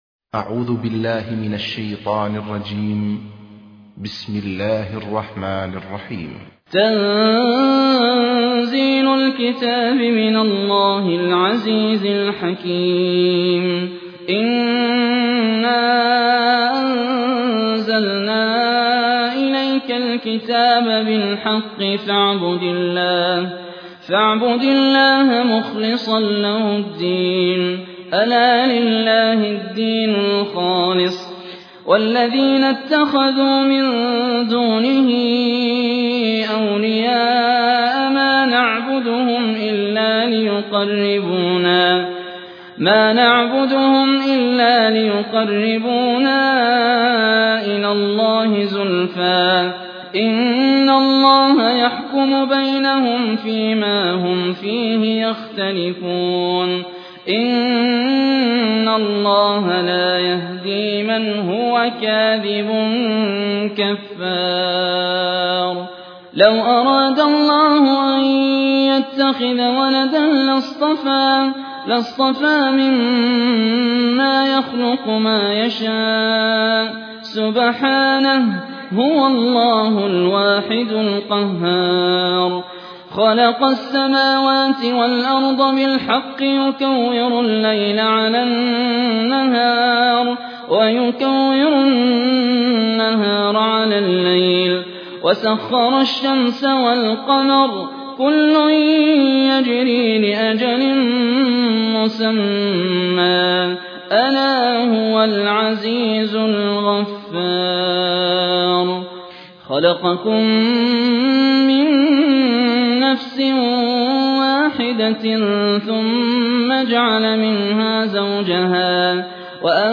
High Quality Quranic recitations and Islamic Lectures from selected scholars